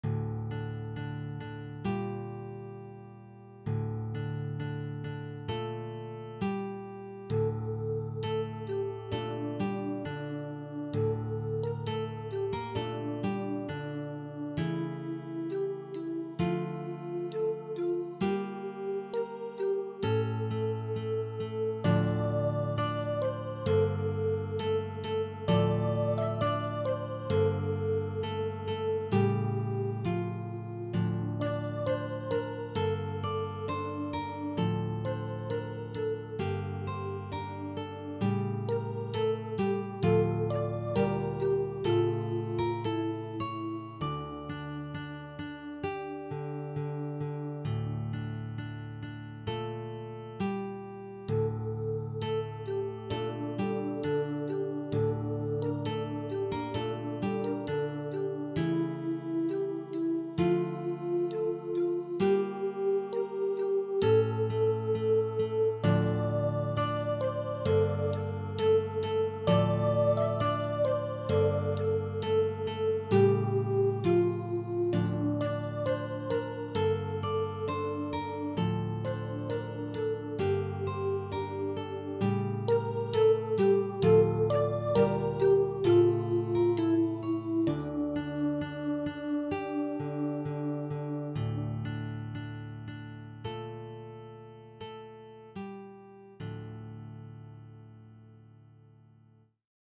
Traditional Russian Song
Arr. for Guitar Quartet/Ensemble
(Listen: Midi File)